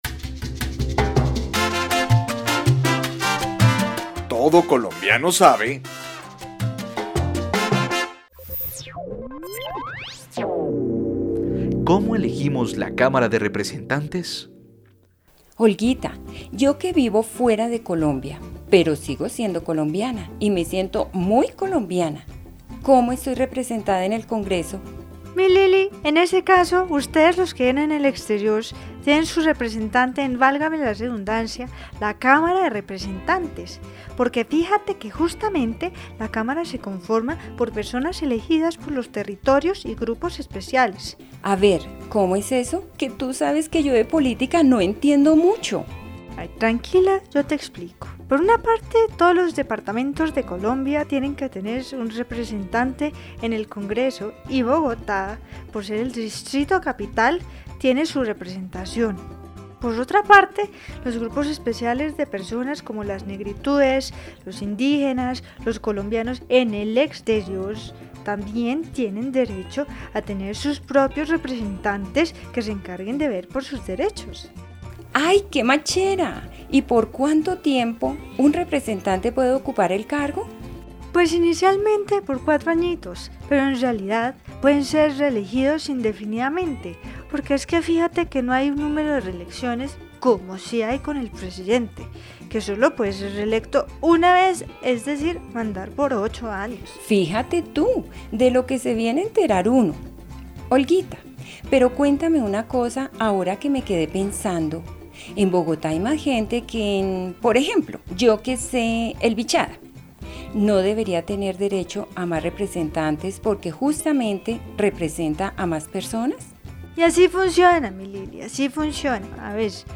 Programas de radio , Participación ciudadana en Colombia , Elecciones y democracia en Colombia , Control político y rendición de cuentas , Irregularidades y delitos electorales , Colombia -- Grabaciones sonoras